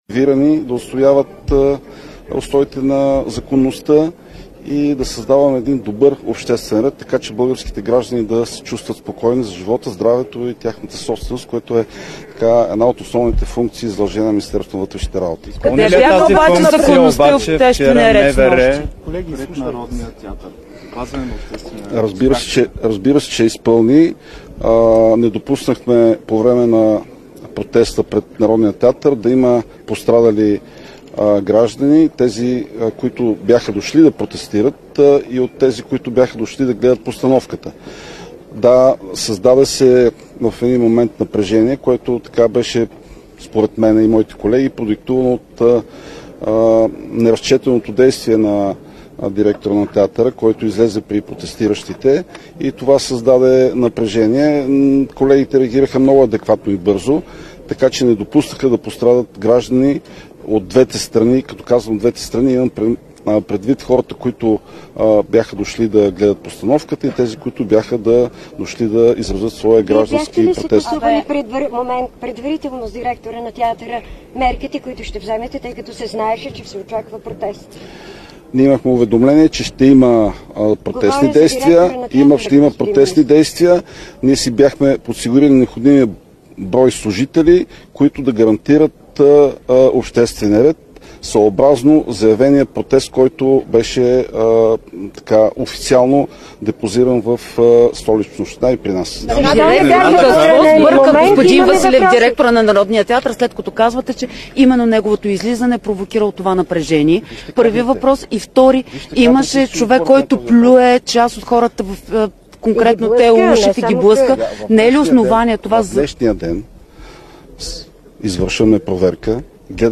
Директно от мястото на събитието
11.55 - Брифинг на министъра на вътрешните работи Атанас Илков след церемонията по отбелязване на професионалния празник на МВР. - директно от мястото на събитието (двора на ГДНП, бул. „Ал. Малинов")